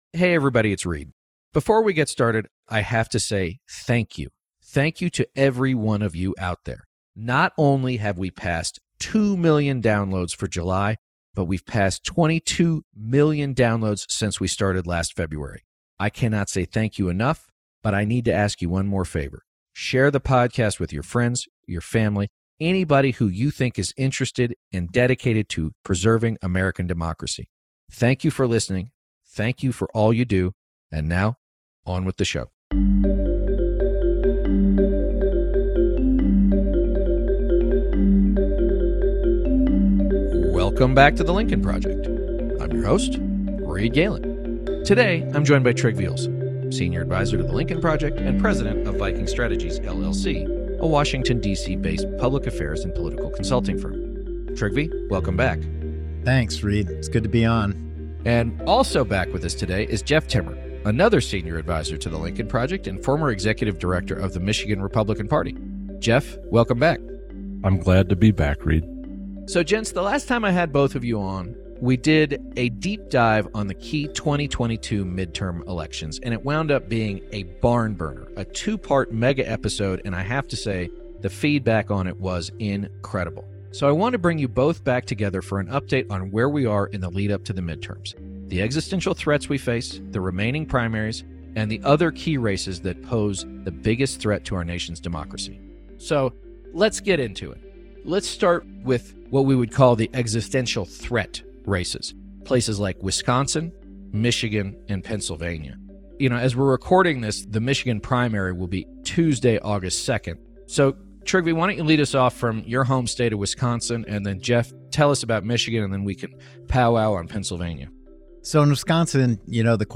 In “Part 1” of a two-part conversation, the panel discusses the latest in the “existential threat” races in Wisconsin, Michigan, and Pennsylvania, what to look for in the upcoming primaries, and why it's so dangerous to underestimate the cavalcade of cartoonish, ultra-maga GOP candidates.